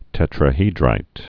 (tĕtrə-hēdrīt)